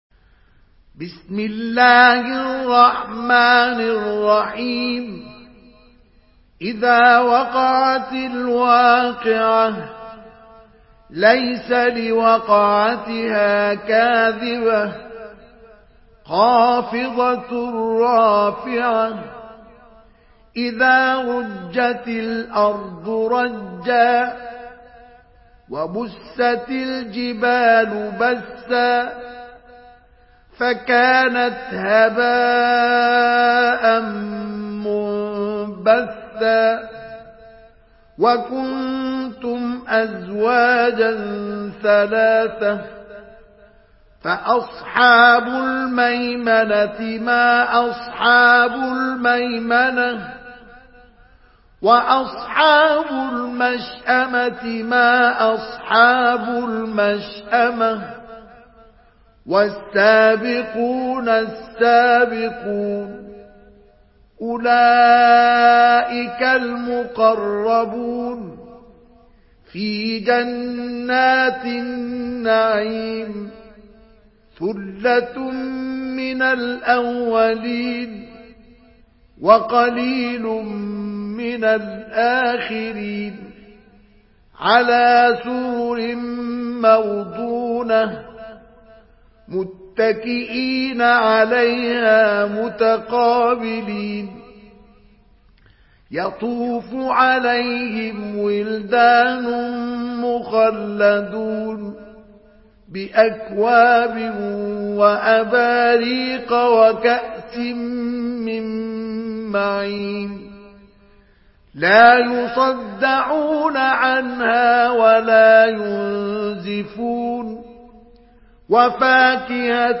Surah Vakia MP3 by Mustafa Ismail in Hafs An Asim narration.
Murattal